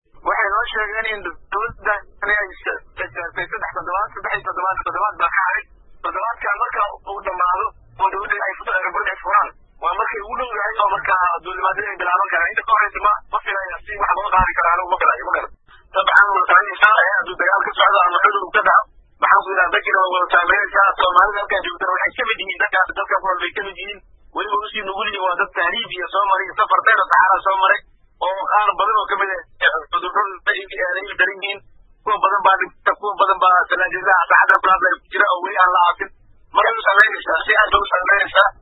Dhageyso: Safiirka Soomaaliya oo ka hadlay xaalada dhalinyaro ku dhibateysan Liibiya | Aragti Cusub
Safiirka Soomaaliya ee dalka Liibiya Muxudiin Maxamed kaalmooy oo ka hadlay cabashada dhalinyarada Soomaaliyeed ayaa sheegay in aysan awoodin in dhalinyaradaasi xiligan loo soo gudbiyo dhinaca Soomaaliya sababo la xiriira dhaqaale xumi heysata safaaradda Laftirkeeda.